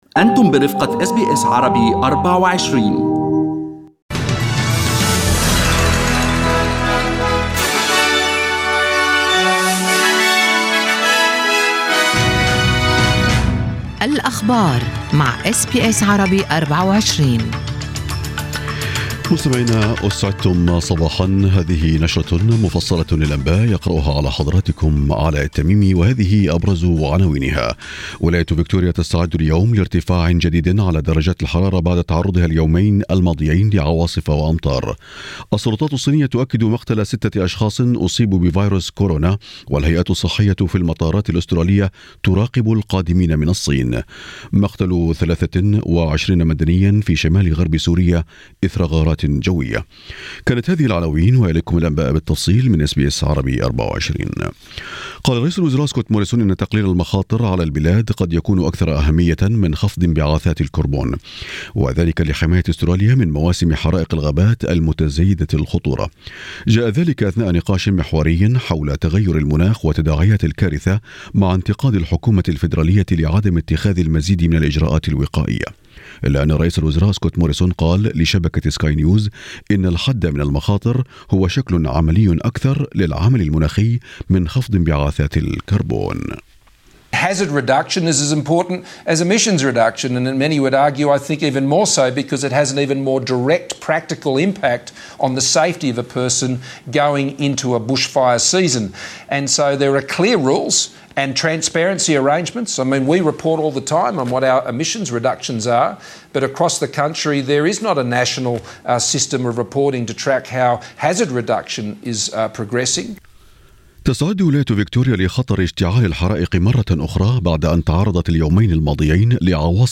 Arabic News Bulletin Source: SBS Arabic24